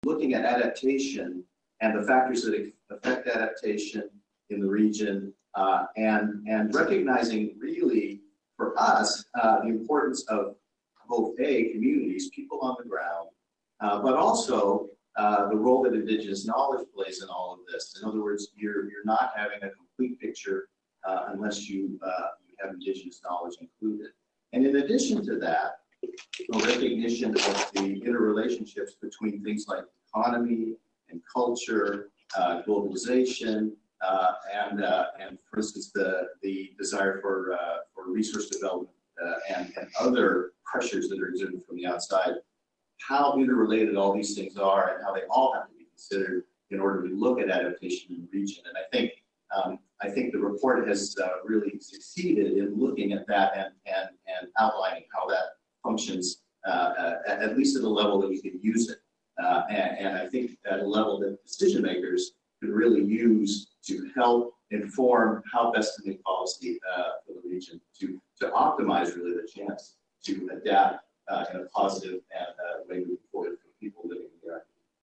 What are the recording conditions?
on Indigenous knowledge and adaptation, from this week’s news conference in Reston, Virginia